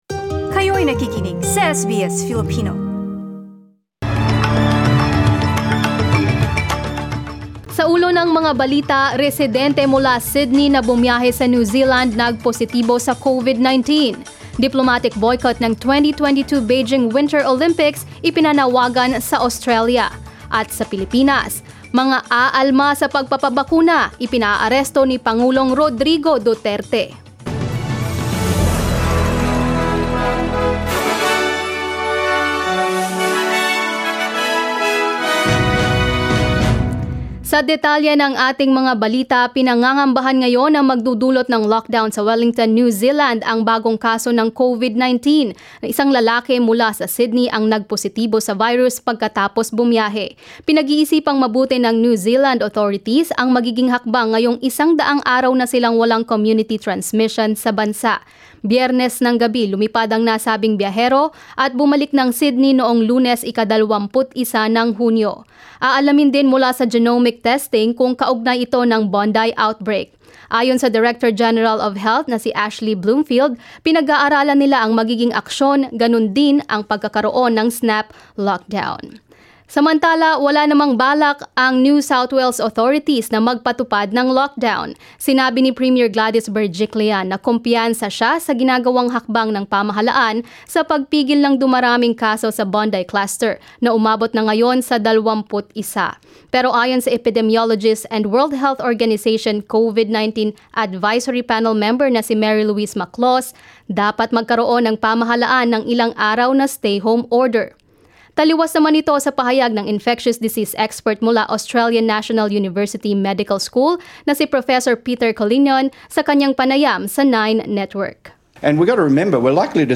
SBS News in Filipino, Wednesday 23 June